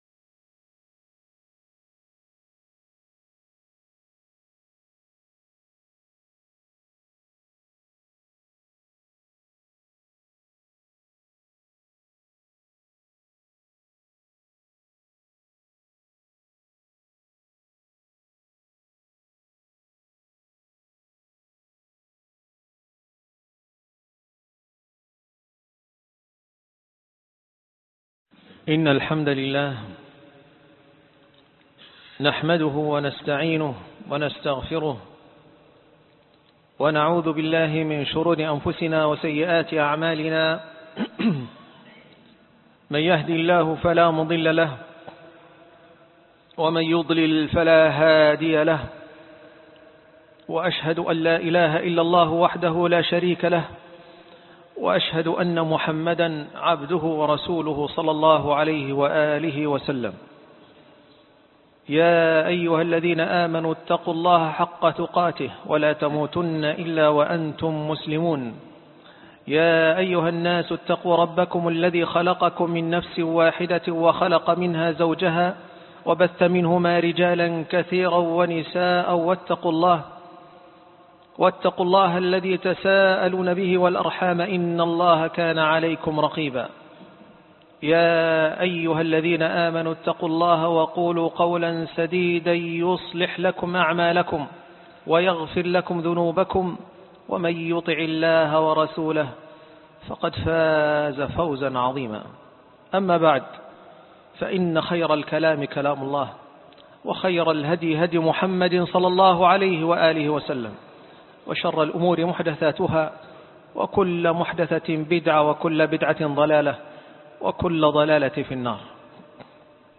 فكر الخوارج الأسباب والعلاج ( الجزء الأول ) - خطبة الجمعة